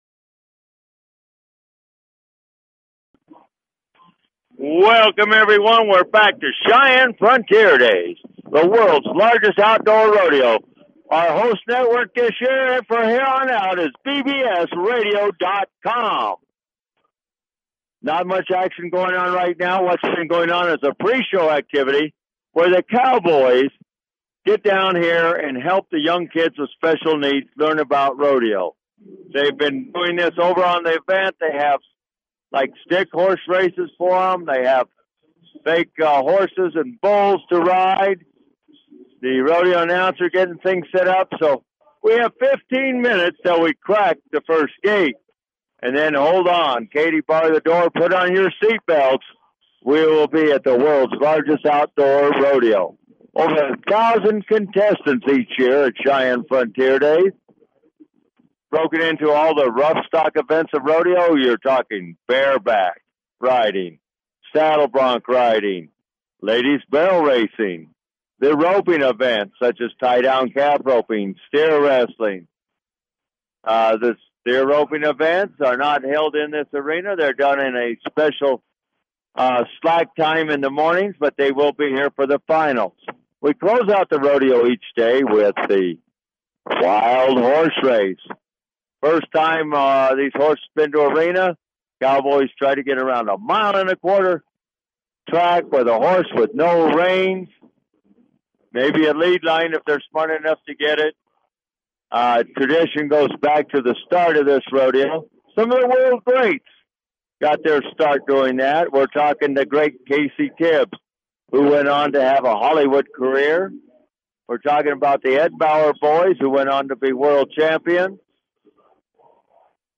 Live play by play rodeo experiences!